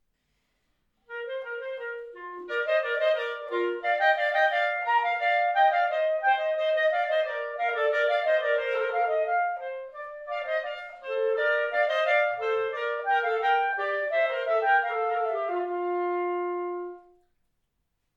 Besetzung: 2 Klarinetten
Suiten für 2 Klarinetten